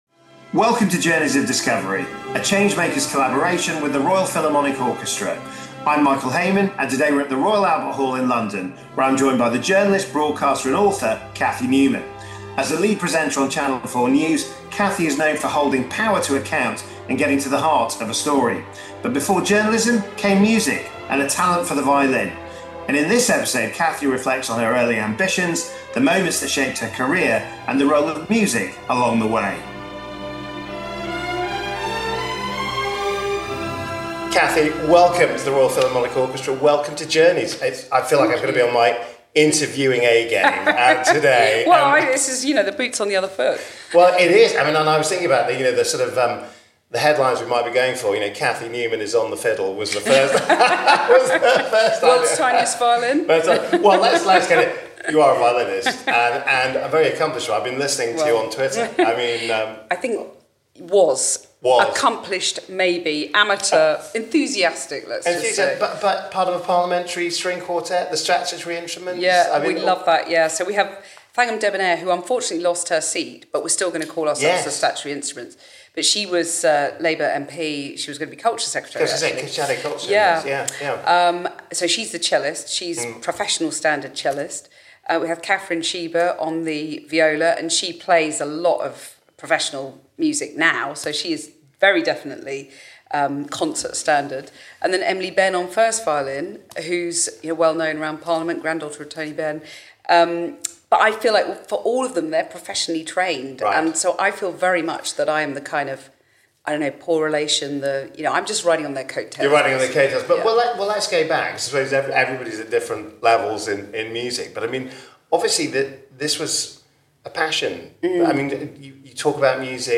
Recorded at the Royal Albert Hall in collaboration with the Royal Philharmonic Orchestra, Cathy reflects on her early musical experiences, the challenges of being a woman in media, and her advocacy for music education through Music for All.